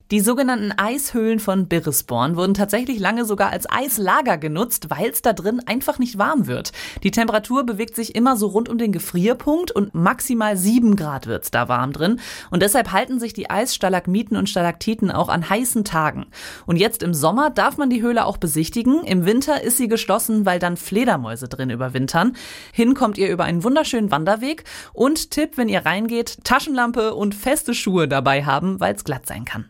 Nachrichten „Die Temperatur liegt immer rund um den Gefrierpunkt“